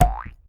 reward_drop_02.ogg